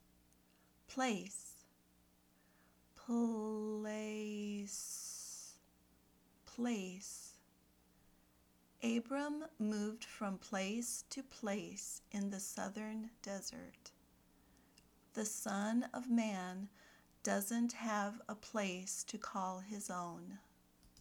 /pleɪs/ (noun)